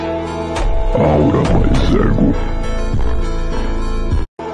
aura ego Meme Sound Effect
Category: Meme Soundboard